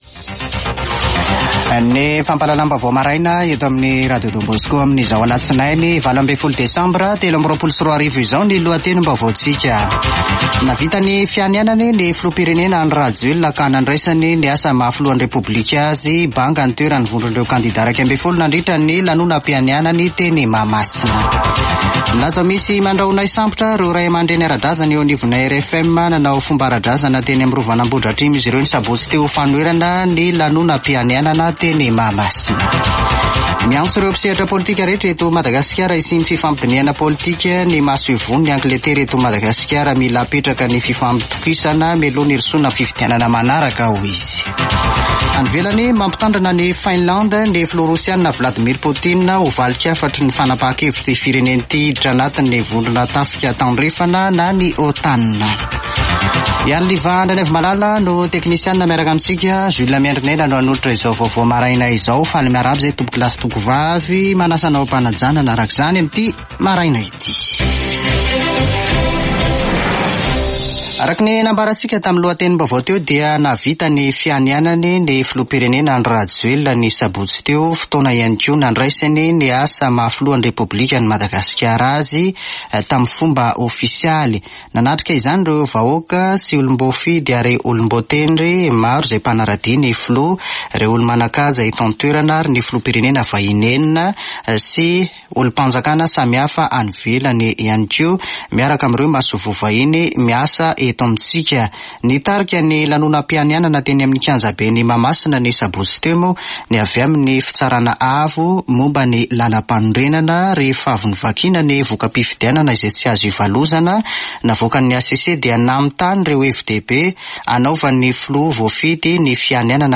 [Vaovao maraina] Alatsinainy 18 desambra 2023